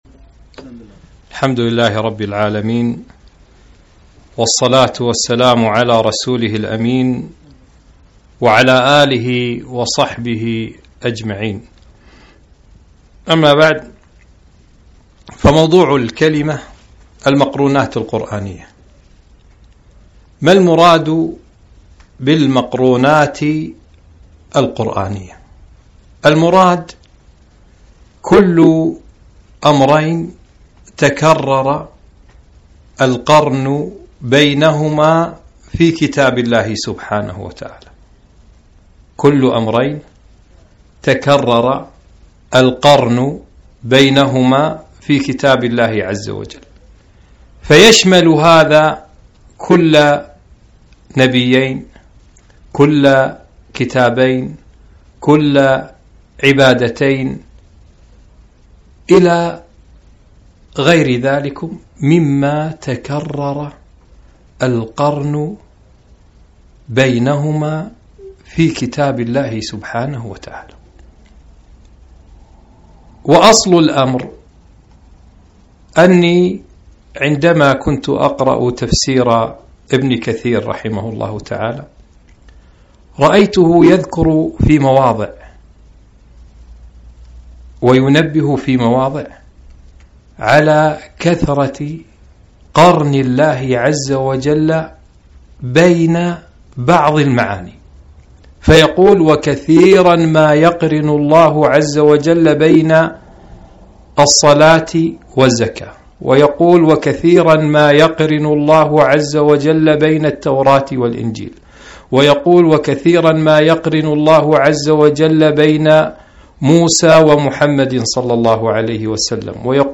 محاضرة - مقرونات القرآن